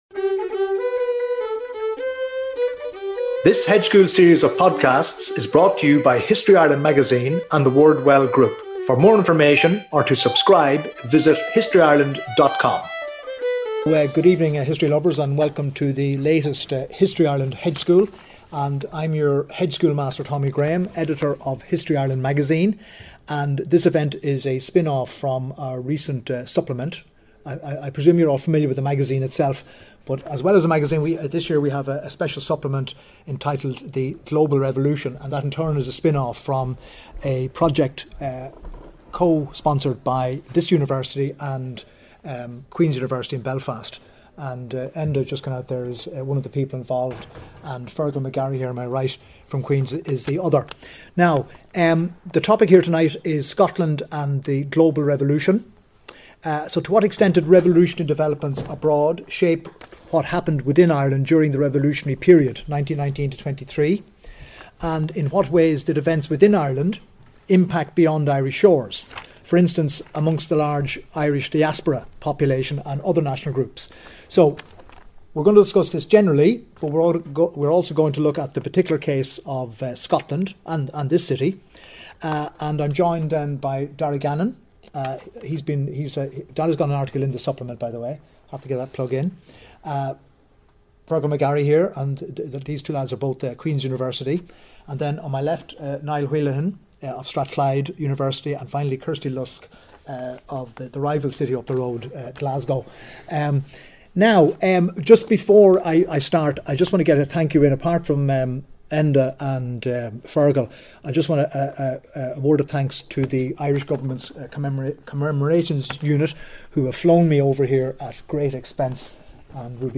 Recorded @ Edinburgh University, Meadows Lecture Theatre, Wednesday 15 May 2019